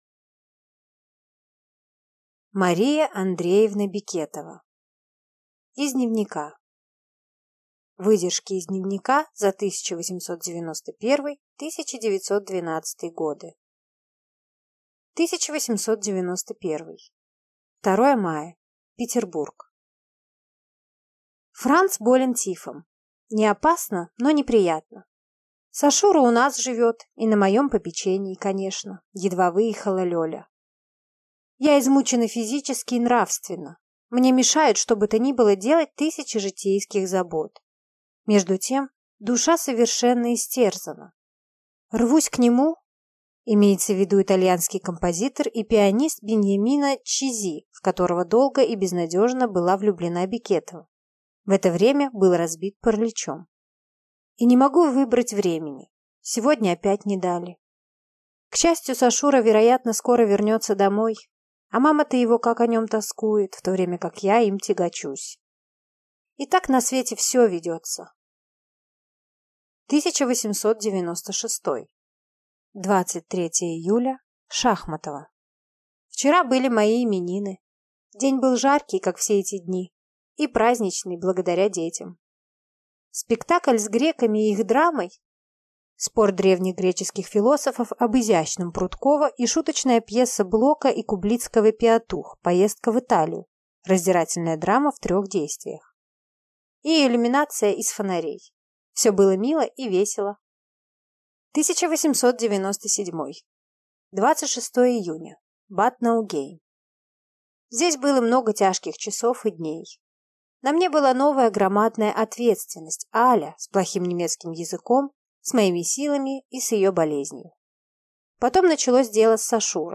Aудиокнига Из дневника